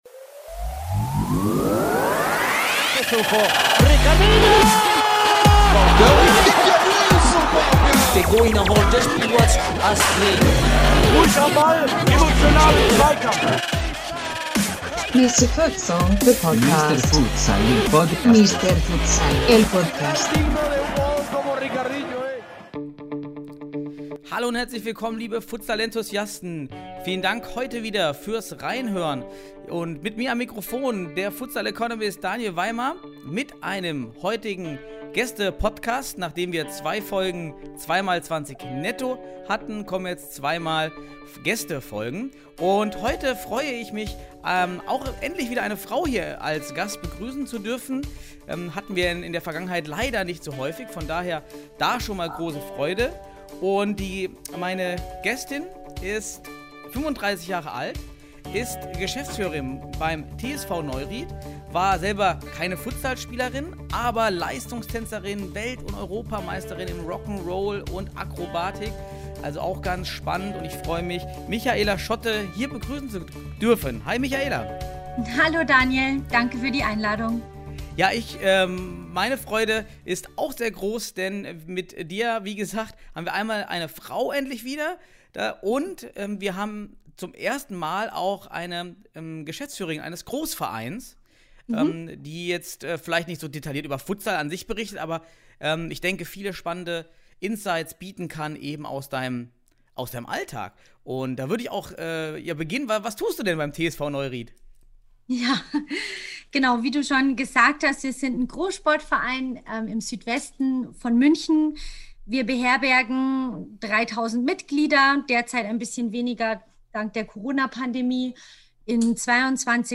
In der 73. Folge des Podcasts sprechen wir endlich mal wieder mit einer Frau über Futsal und zwar genauer über den TSV Neuried aus Bayern.